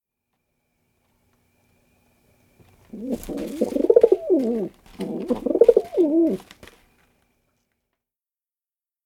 Rock Pigeon
How they sound: Rock Pigeons sing a prolonged series of throaty coos .